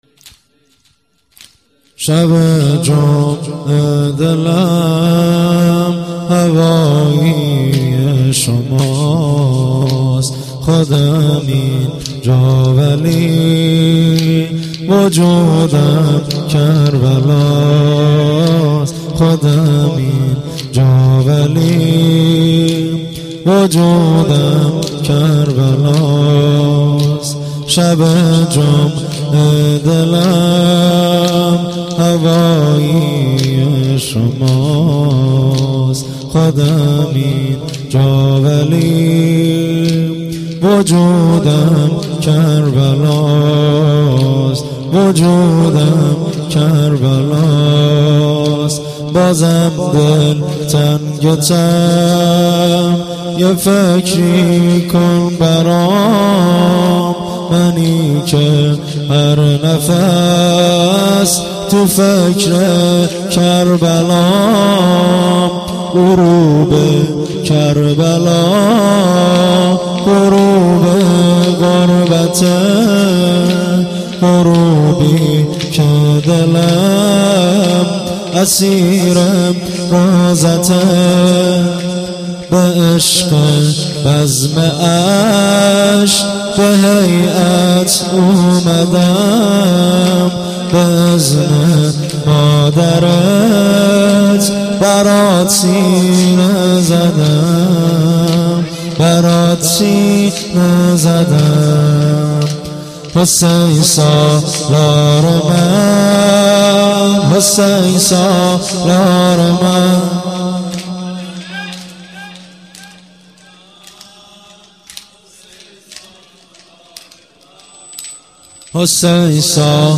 واحد - شهادت حضرت میثم تمار ره 1393